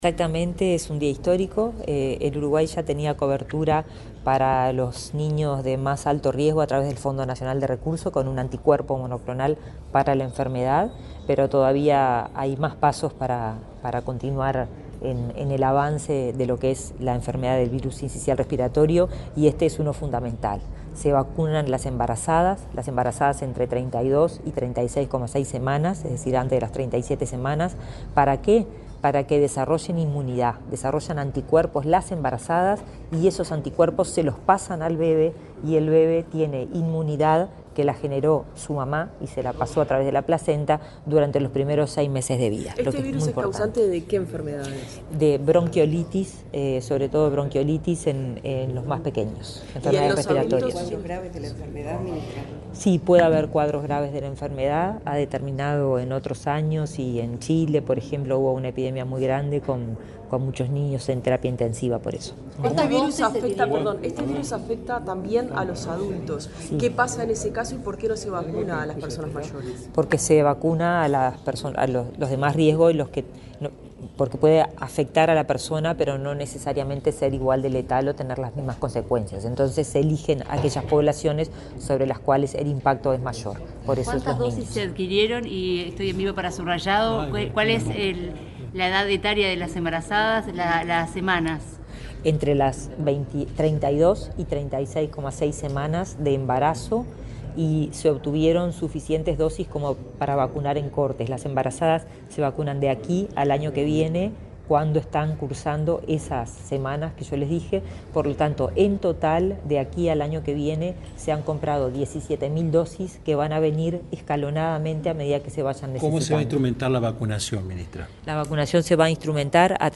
Declaraciones de la ministra de Salud Pública, Karina Rando
Luego, Rando dialogó con la prensa.